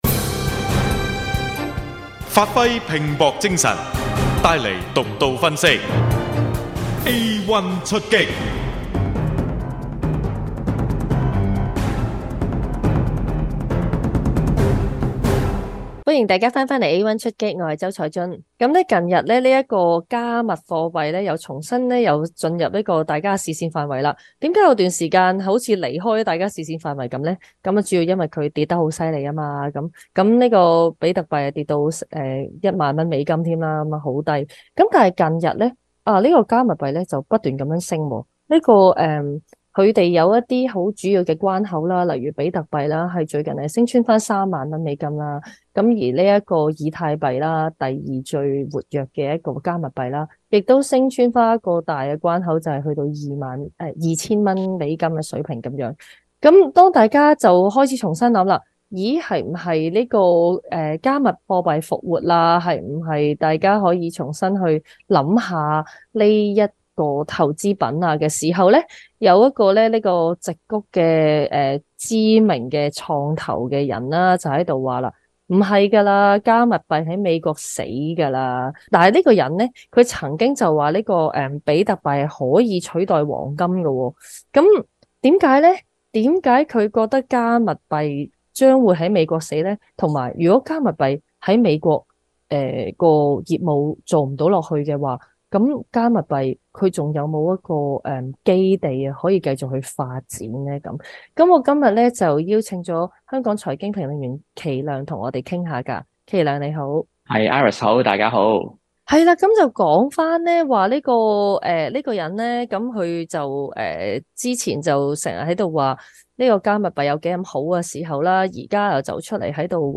【星岛图解】全球最贵油价排行榜 香港夺冠、加拿大排第75名 【有声访问 / A1电台YouTube频道】战火会推高通胀吗？